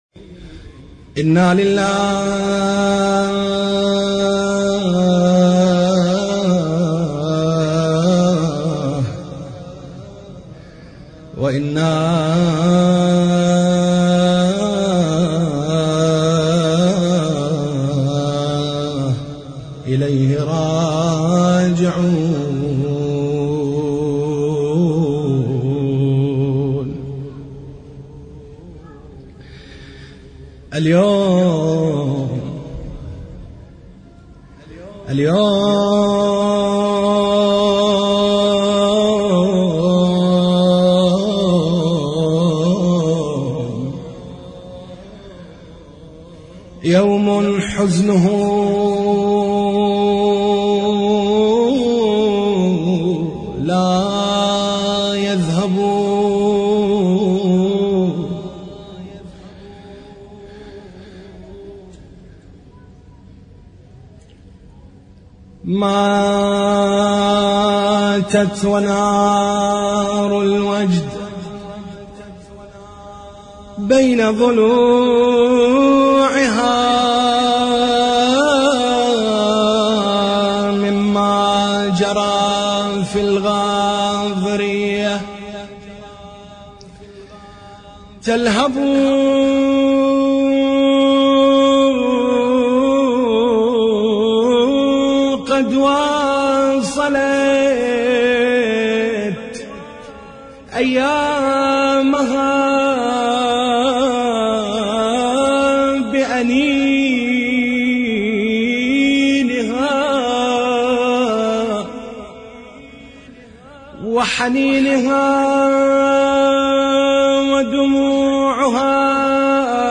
مرثية للسيدة زينب(س)